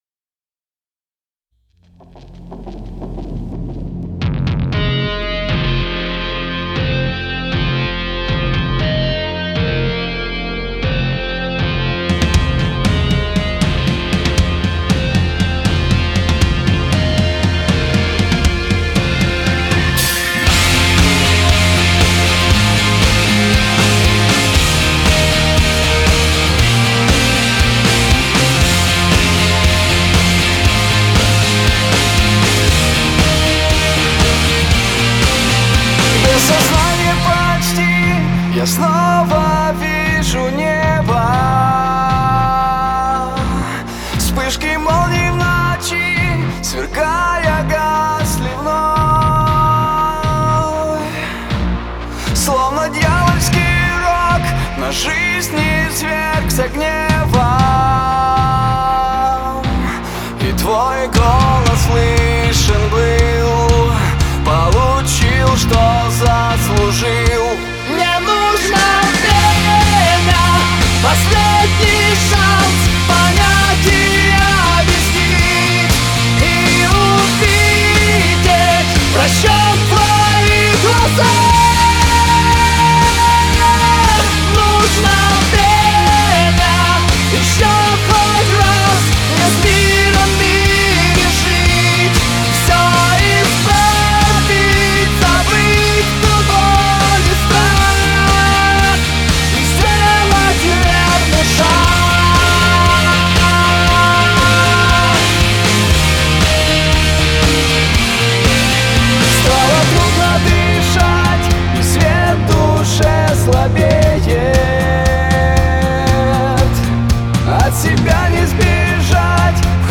Категория: Альтернатива